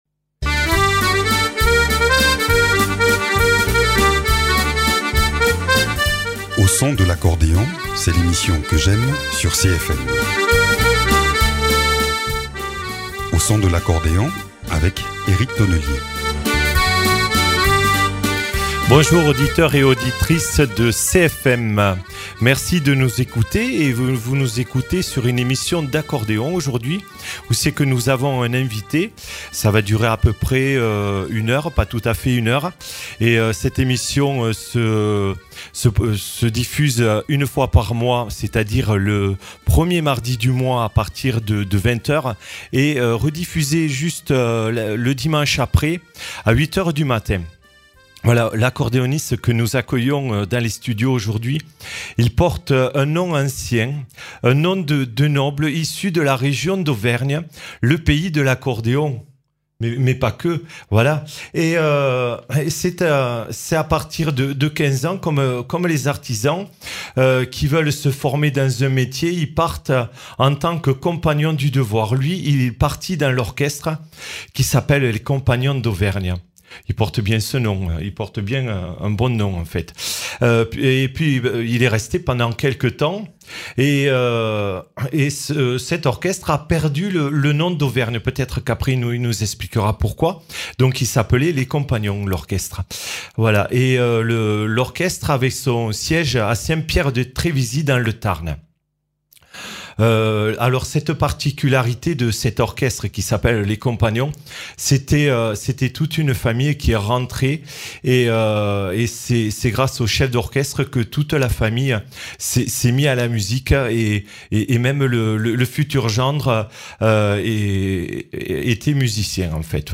C’est un ami de longue date que je reçois aujourd’hui. Il vient nous parler de ses débuts, de sa vie de musicien, il nous partage des souvenirs tout en nous jouant quelques morceaux en live.
accordéoniste.